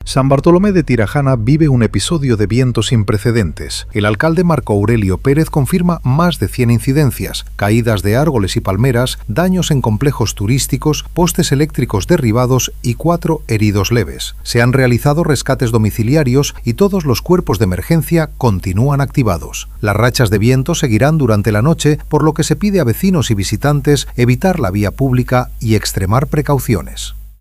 El alcalde de San Bartolomé de Tirajana, Marco Aurelio Pérez, presidió en la tarde de hoy una rueda de prensa urgente en las dependencias de la Policía Local para informar sobre la situación extraordinaria que ha afrontado el municipio a lo largo de la jornada como consecuencia del episodio de fuertes vientos y fenómenos costeros que afecta a Canarias.